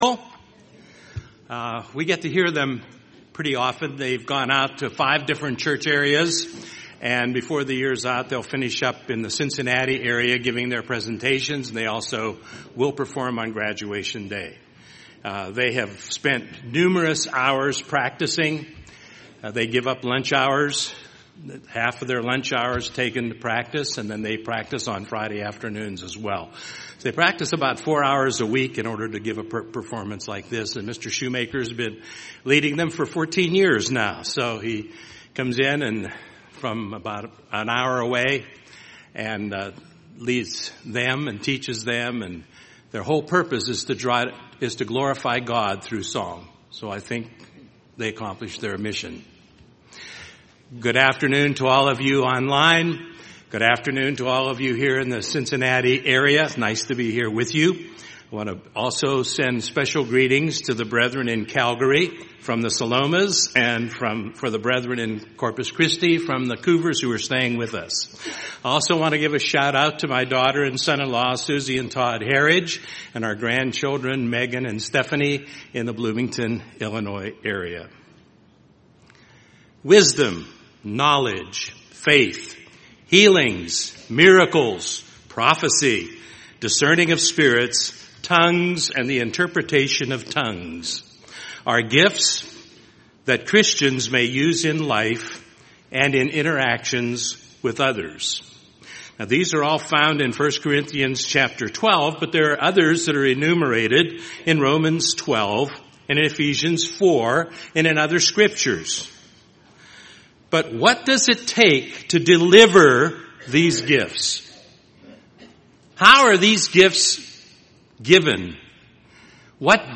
An explanation of the fruits and gifts of God's Holy Spirit. This was a split sermon given at the 2013 GCE.